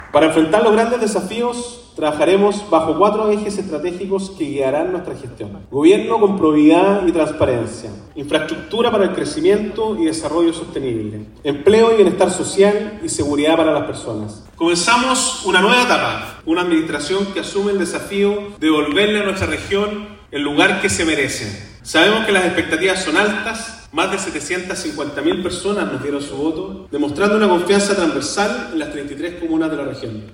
Con el Teatro Regional Bíobío lleno, asumió Sergio Giacaman, el candidato a gobernador más votado de la región del Bío Bío.
En su discurso de asunción, Giacaman abordó temas como fortalecer los lazos con Argentina, el terrorismo en la provincia de Arauco, el Plan de Fortalecimiento Industrial, la tardanza en materia de Infraestructura en la región y la necesidad de inyectar recursos a las policías.